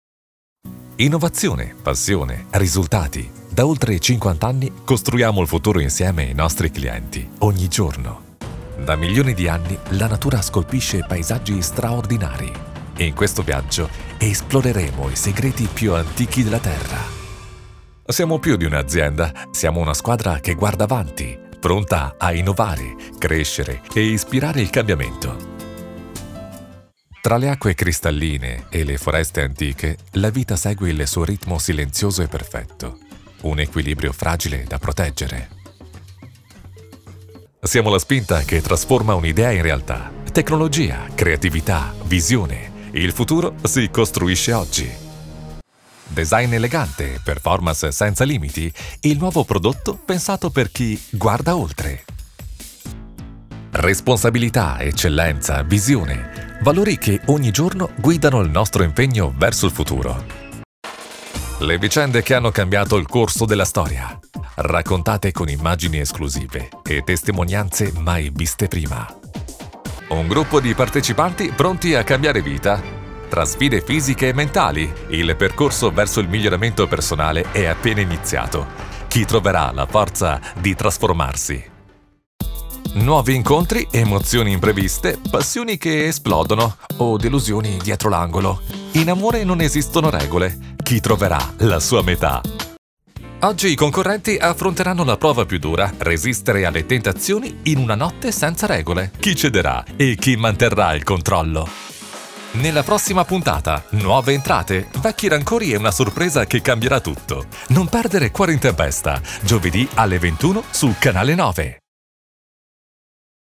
Male
My voice is young and versatile. Warm for narrations, dynamic for commercials, smooth and professional for presentations.
Radio Commercials